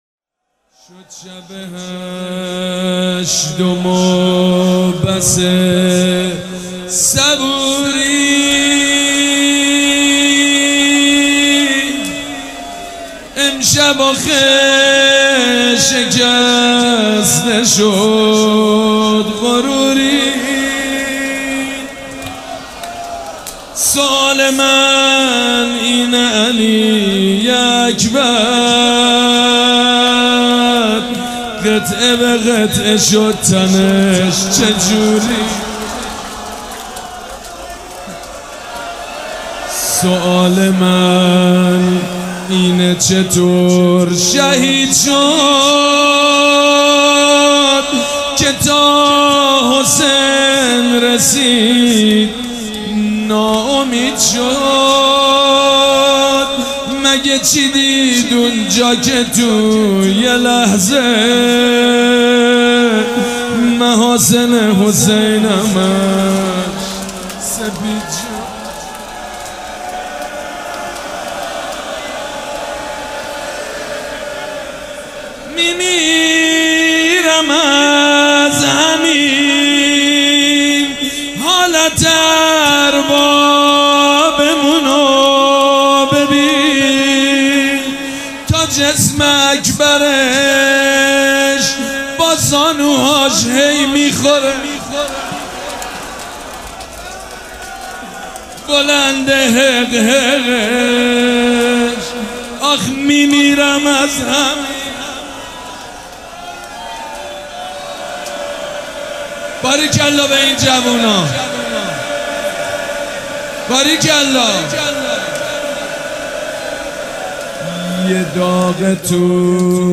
روضه بخش چهارم
شب هشتم محرم 98
مداح حاج سید مجید بنی فاطمه
مراسم عزاداری شب هشتم